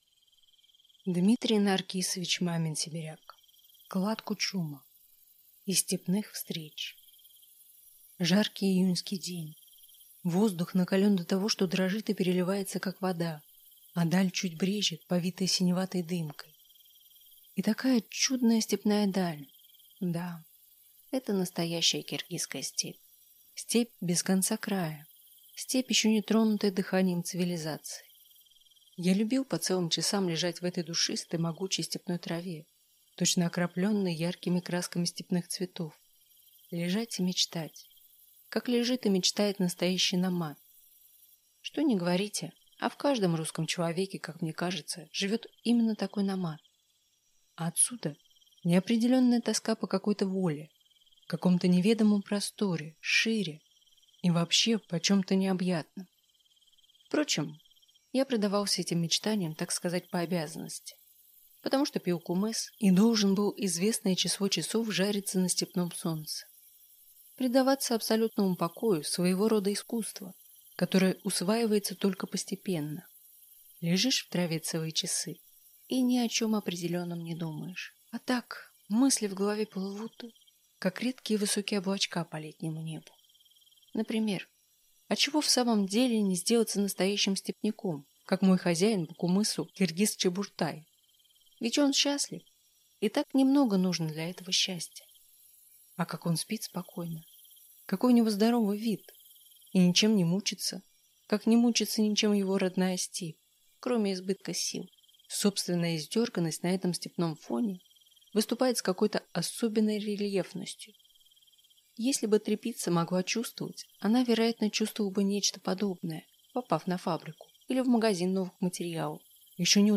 Аудиокнига Клад Кучума | Библиотека аудиокниг